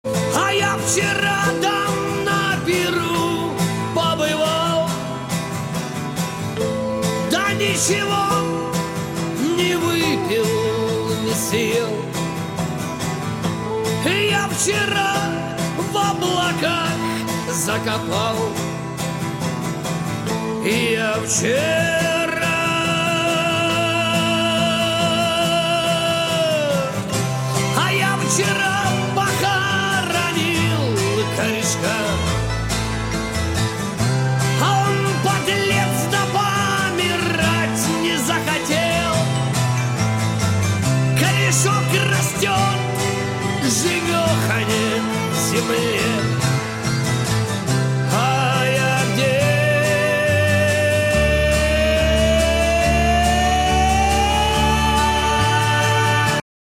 • Качество: 128, Stereo
русский рок